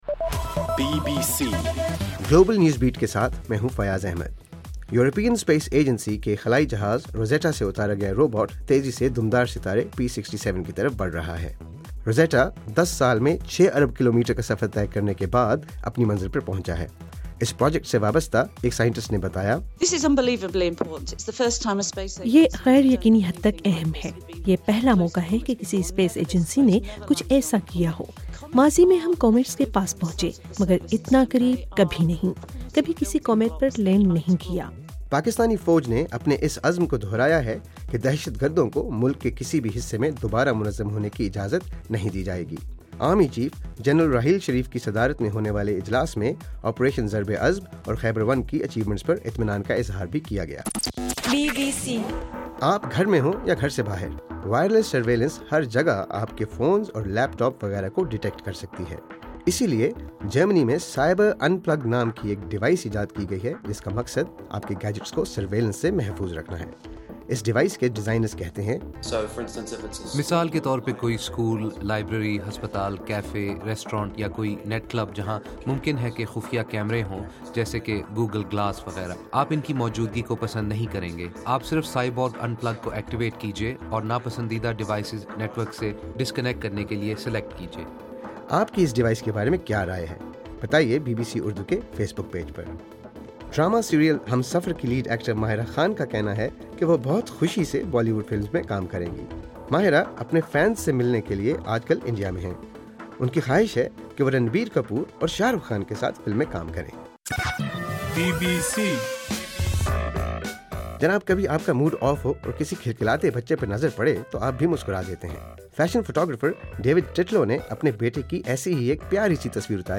نومبر12: رات 10 بجے کا گلوبل نیوز بیٹ بُلیٹن